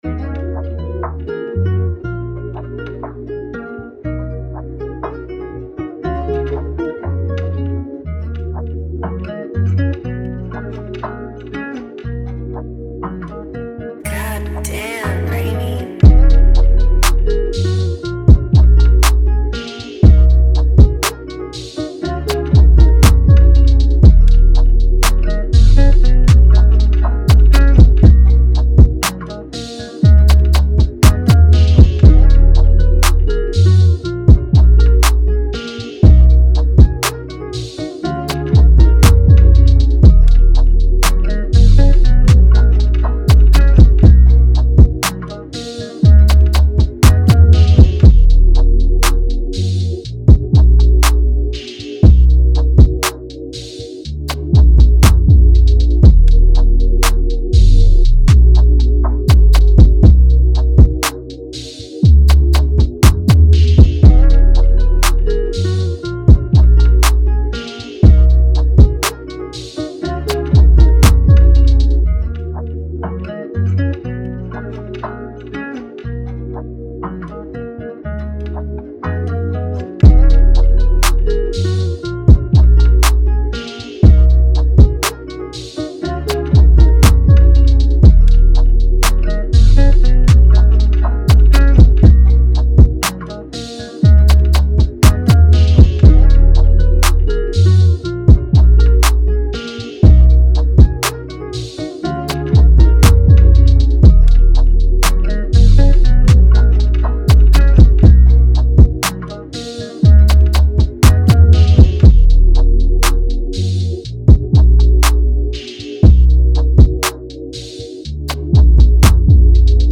120BPM (RNB/GUITAR/RAP) CO